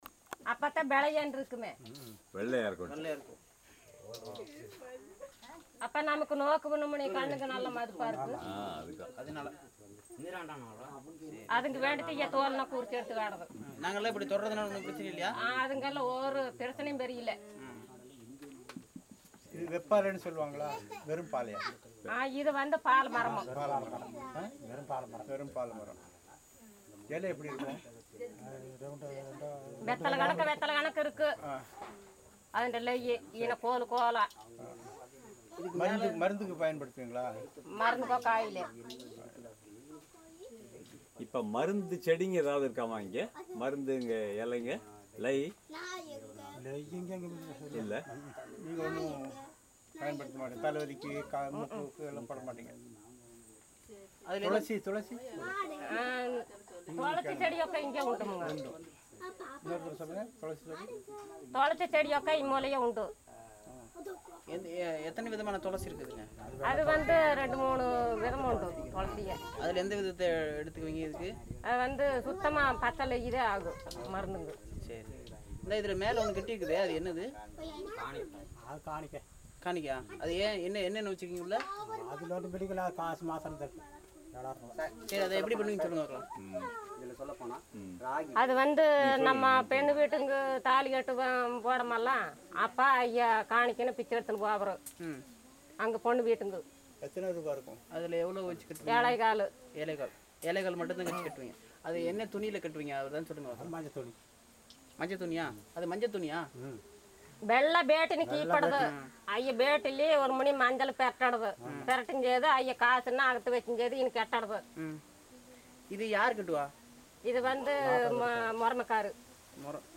Conversation about marriage ornaments and traditions
NotesThis is a conversation between the principal investigator and consultants about medicinal trees, and marriage traditions, especially related to dowry, ornaments that both bride and groom wear, and what happens immediately after a wedding.